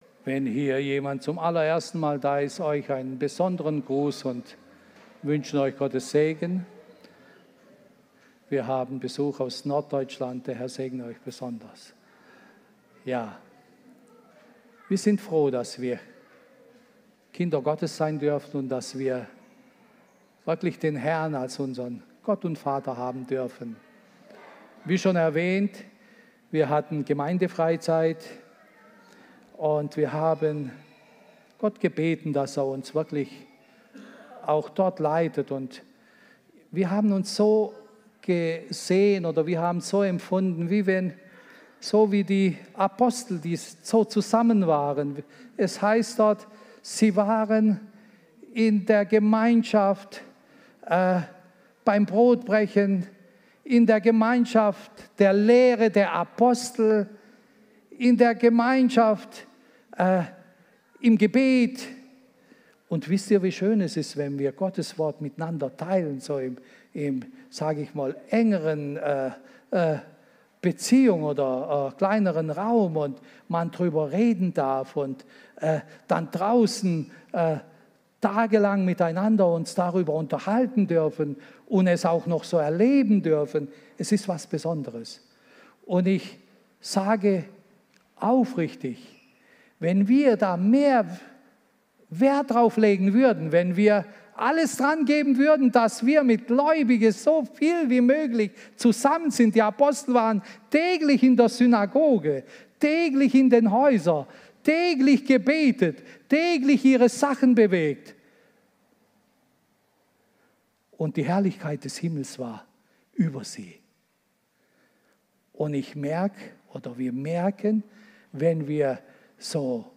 Predigt
im Christlichen Zentrum Villingen-Schwenningen.